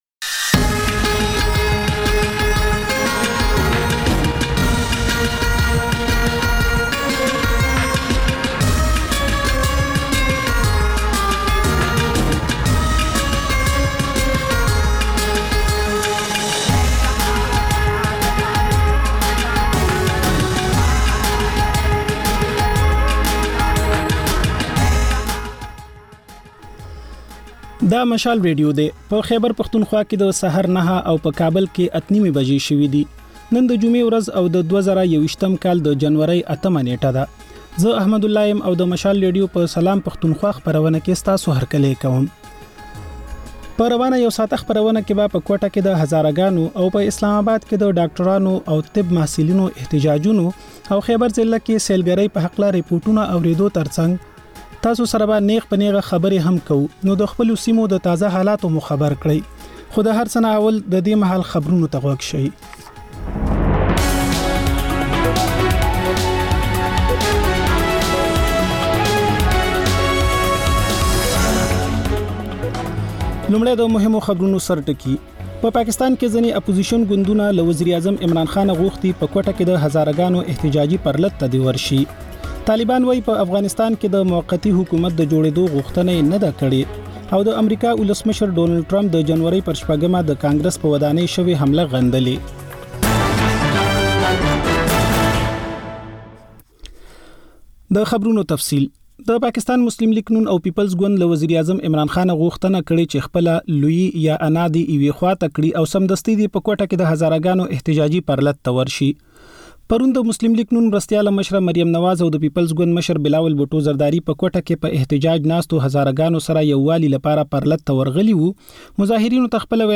دا د مشال راډیو لومړۍ خپرونه ده چې په کې تر خبرونو وروسته رپورټونه، له خبریالانو خبرونه او رپورټونه او سندرې در خپروو.